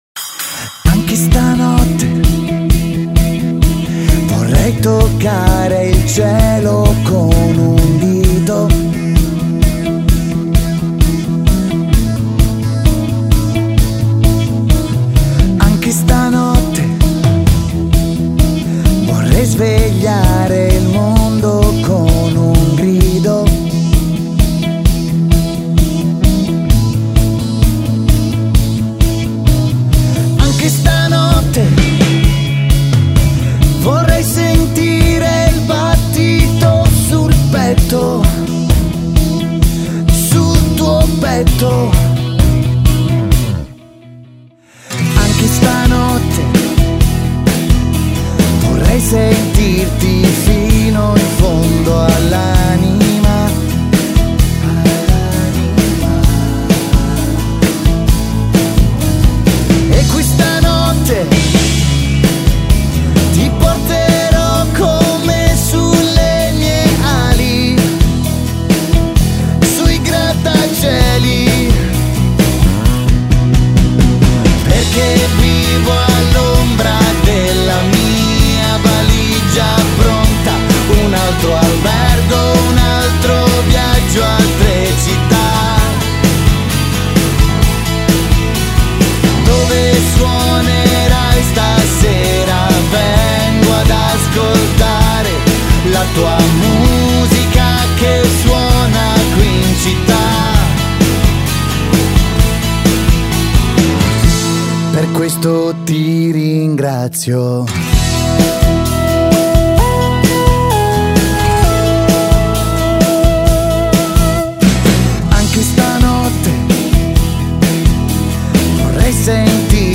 Pop / Rock Italian style.